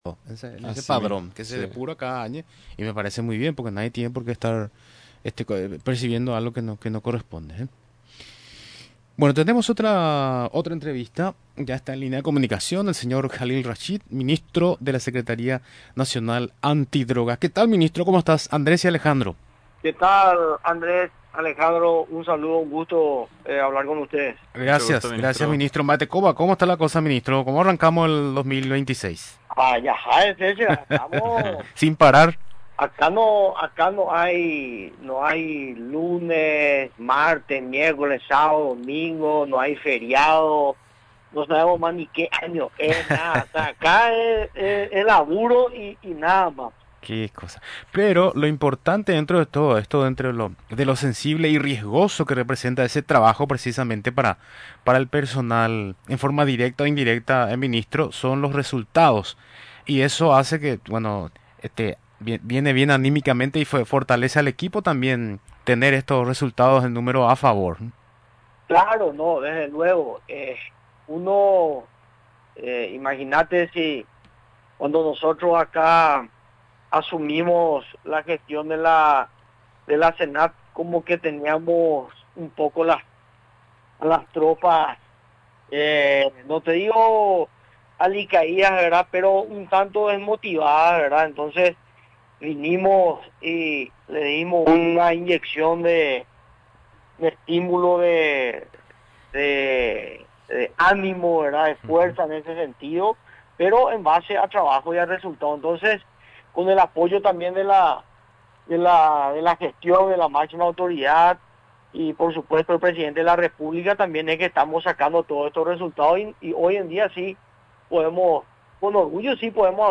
A través de su Oficina Regional de Bella Vista Norte, la Senad realizó un operativo de erradicación de cultivos ilícitos en etapa de crecimiento en una zona utilizada para actividades vinculadas al narcotráfico, específicamente en el sector de la Estancia Ñe’ã, explicó este jueves el ministro de la Secretaría Nacional Antidrogas, Jalil Rachid.